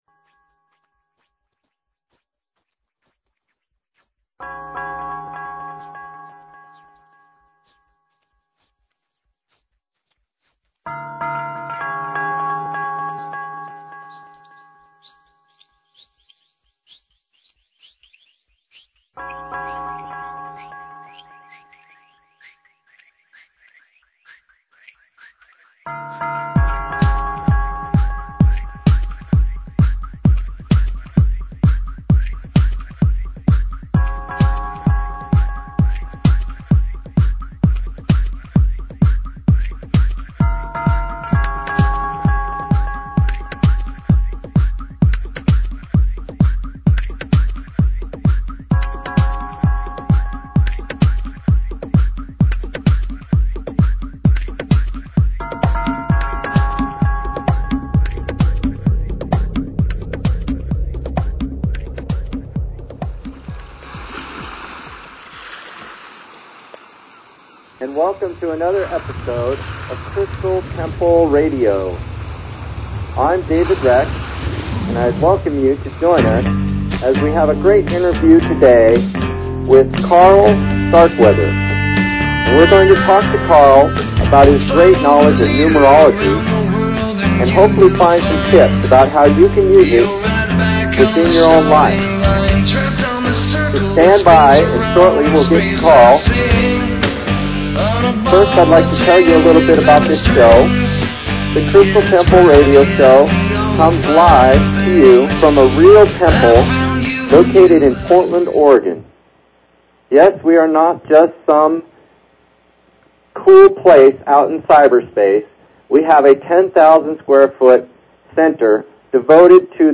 Talk Show Episode, Audio Podcast, Crystal_Temple_Presents and Courtesy of BBS Radio on , show guests , about , categorized as